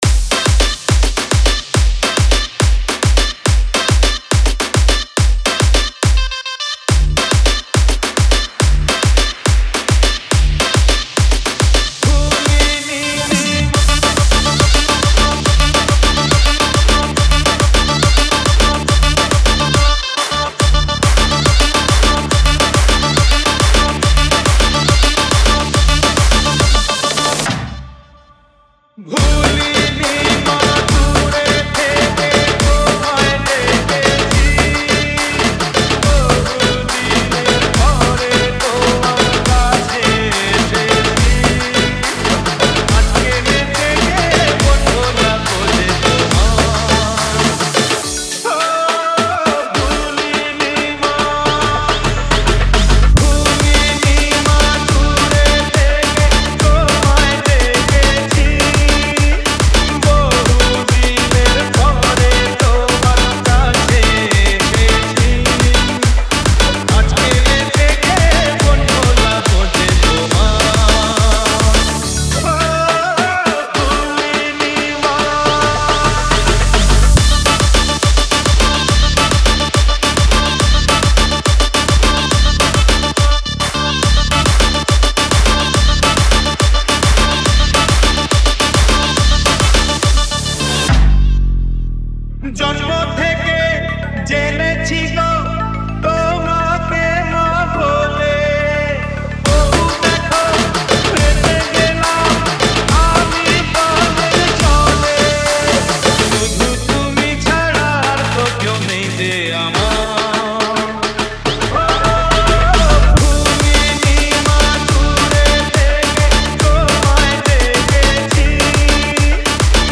Tollywood Single Remixes